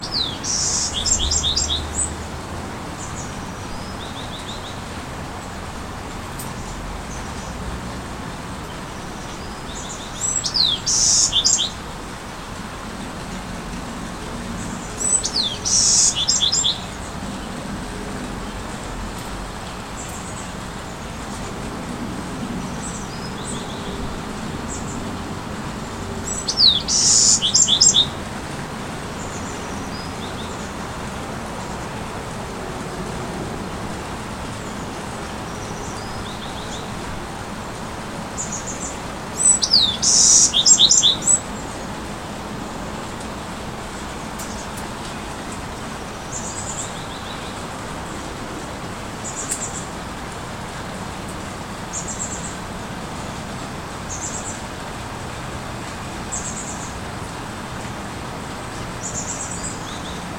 Full and long Bewick's Wren songs proclaim spring even before it arrives, while mournful Golden Crowned Sparrows announce a three-note, descending autumn in October.
{Editor's note: Below are two recordings of bird songs:
bewicks wren.mp3